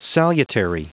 Vous êtes ici : Cours d'anglais > Outils | Audio/Vidéo > Lire un mot à haute voix > Lire le mot salutary
Prononciation du mot : salutary